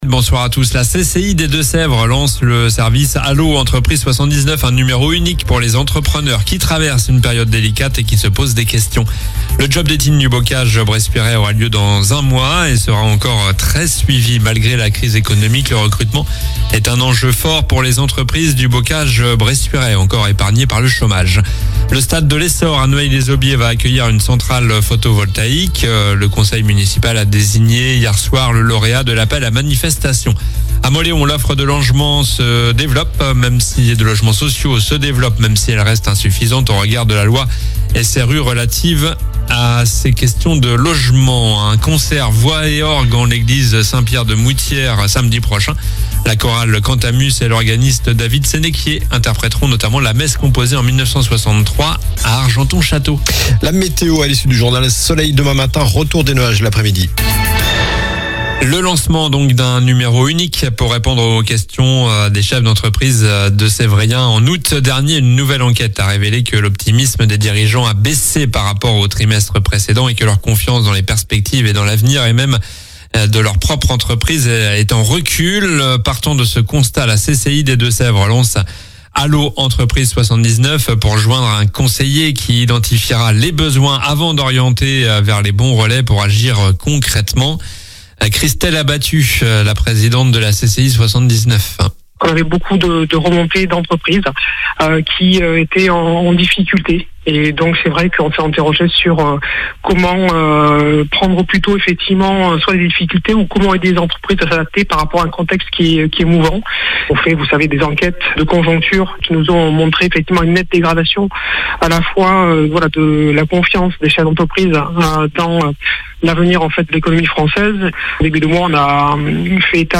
Journal du jeudi 25 septembre (soir)